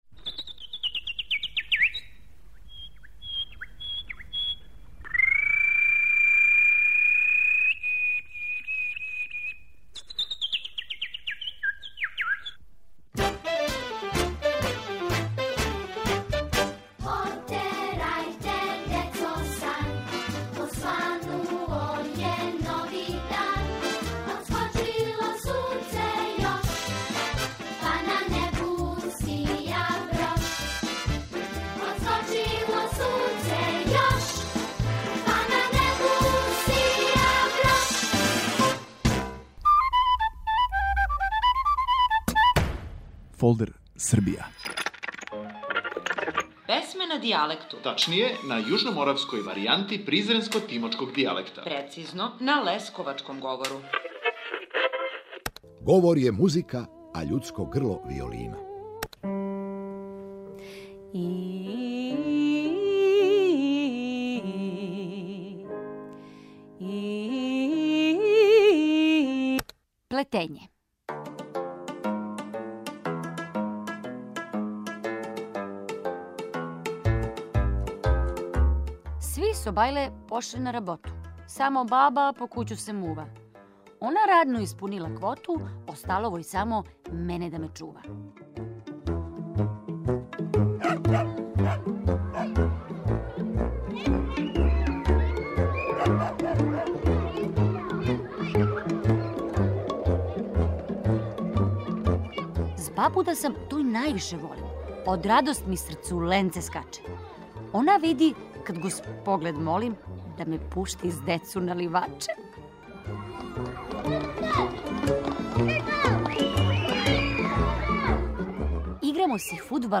на јужноморавској варијанти призренско - тимочког дијалекта, прецизније - на лесковачком говору.